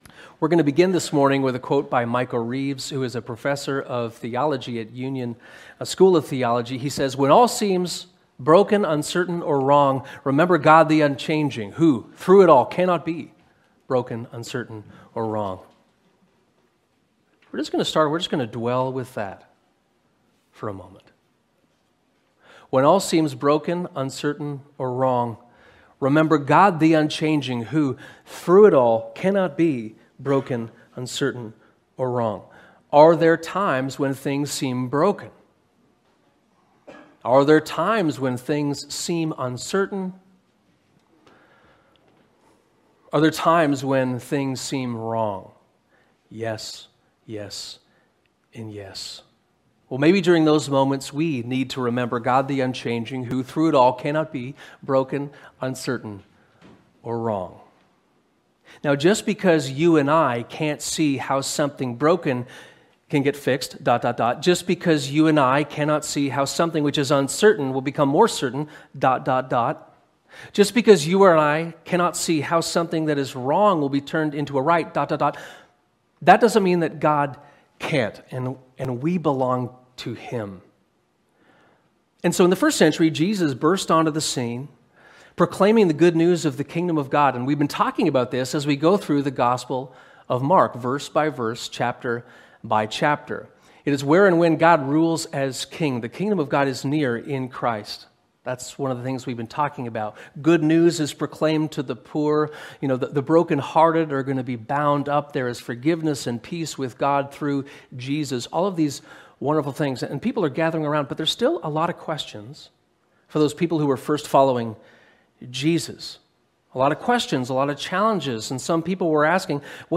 Sermons | Westminster